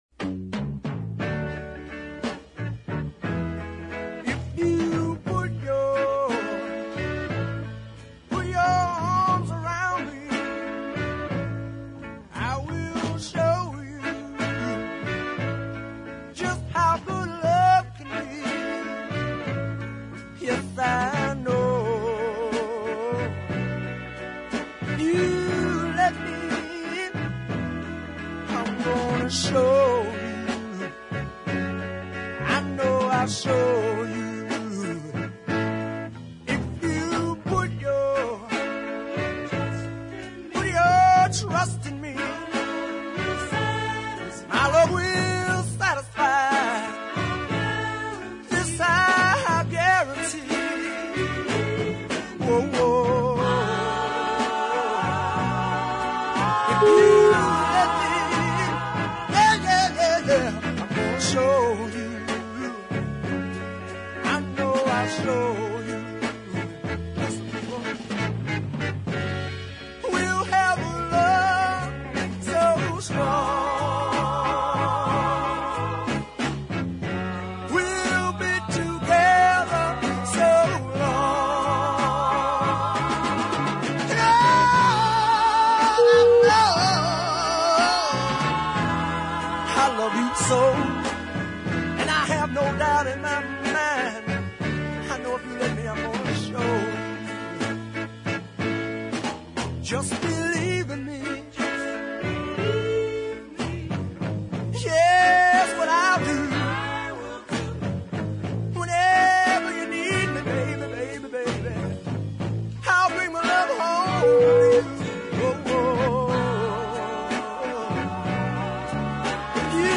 soul combo